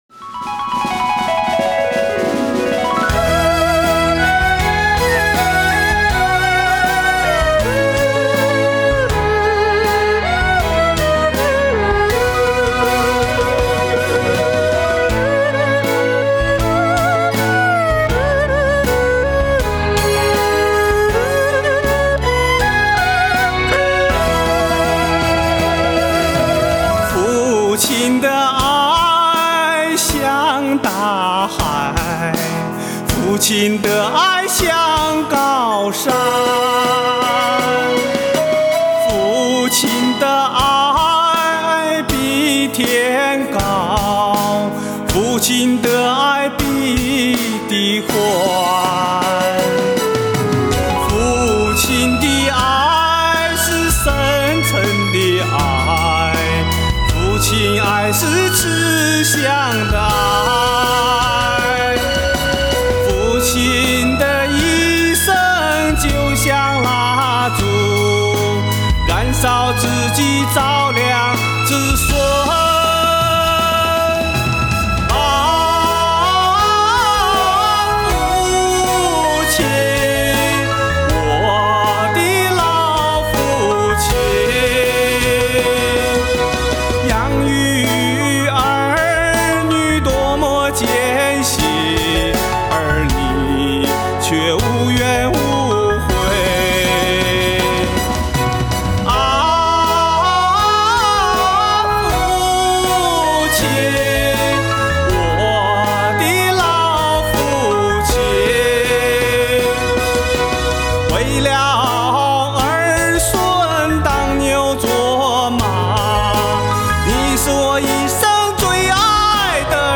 标题: 原创歌曲：父亲的爱 [打印本页]